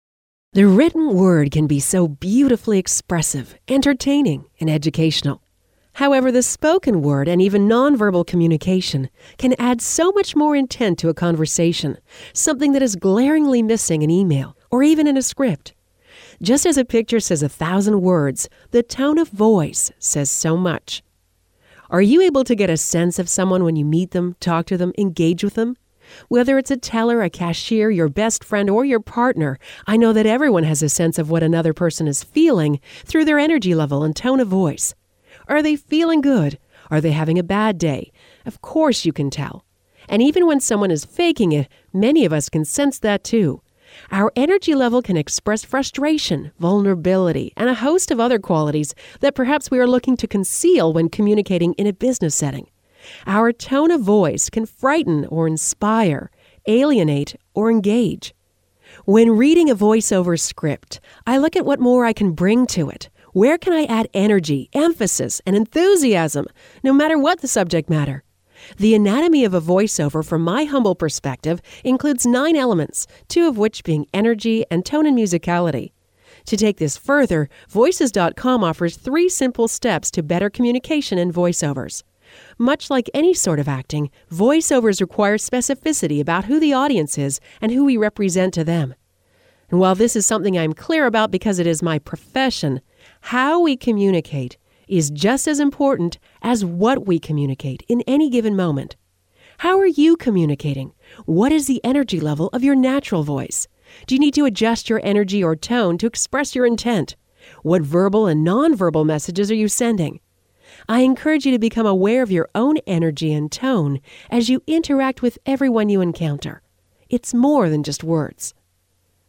(audio version of blog below)